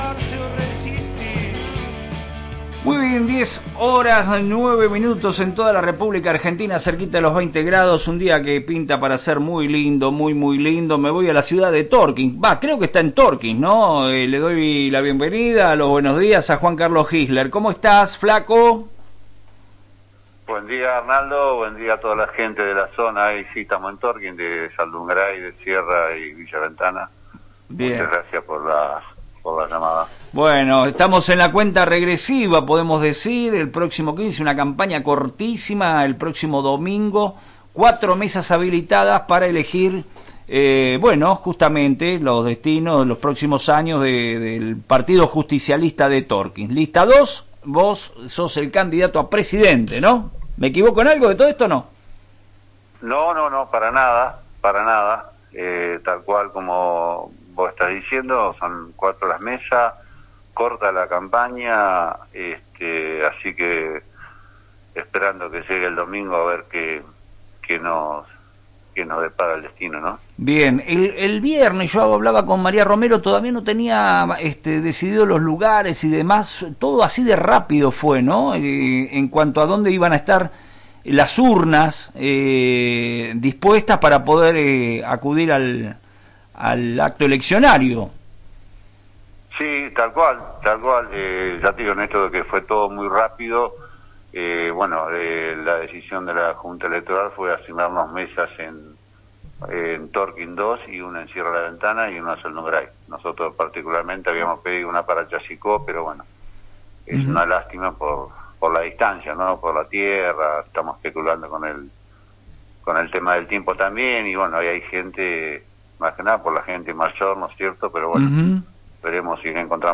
brindó detalles en FM Reflejos sobre la preparación de su espacio y no ahorró críticas hacia la conformación de la lista opositora.